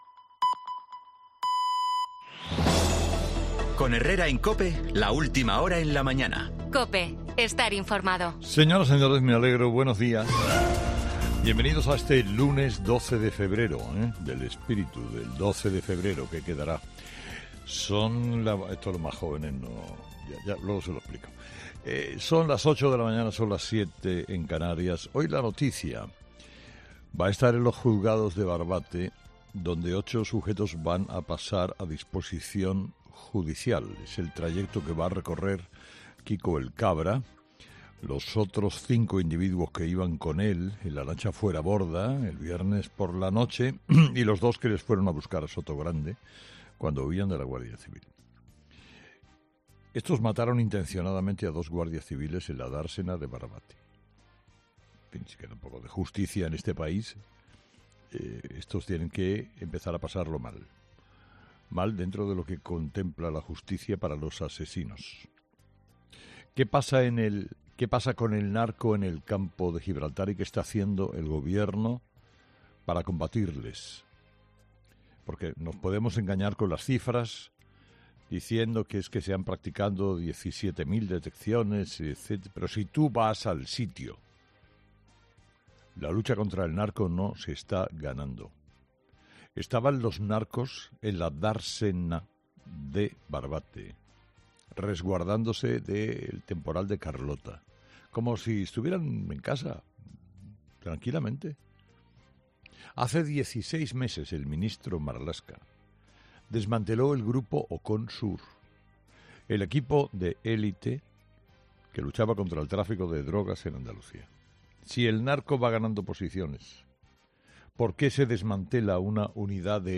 Escucha el monólogo de Herrera de este lunes 12 de febrero de 2024